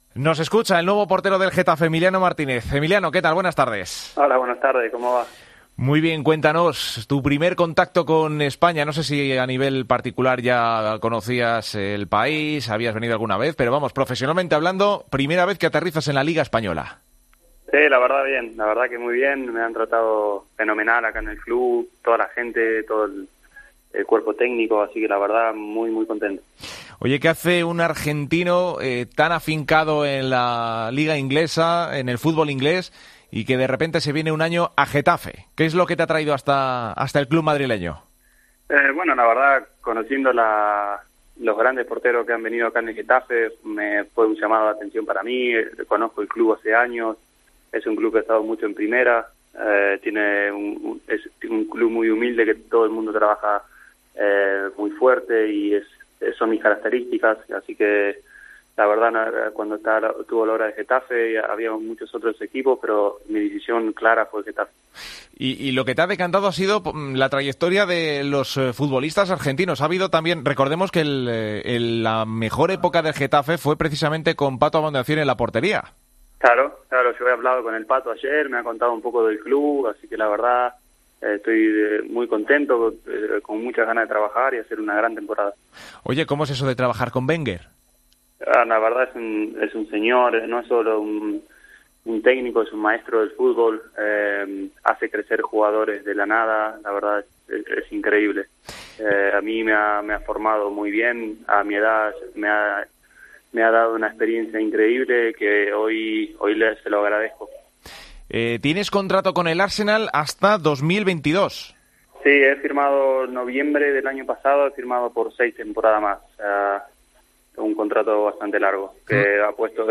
Emiliano Martínez pasa por los micrófonos de Deportes COPE tras su fichaje por el Getafe.